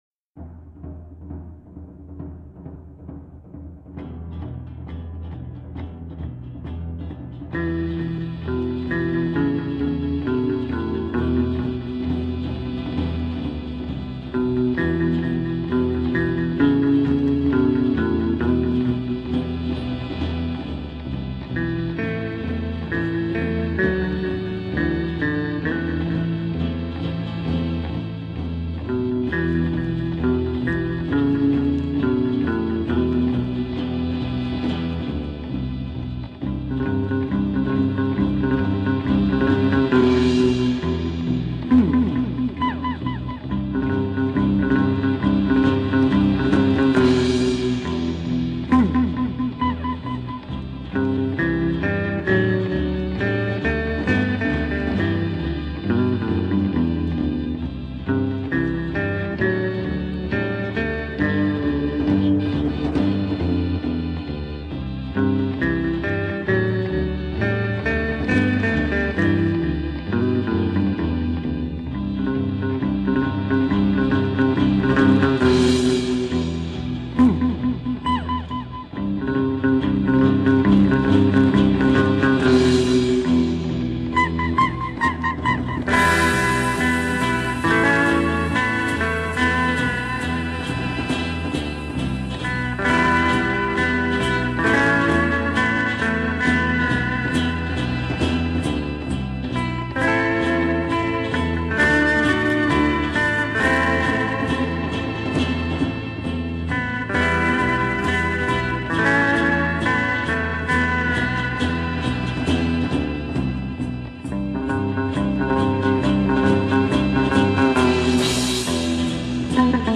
Жанр: Folk-Rock, Эстрада, Soft Rock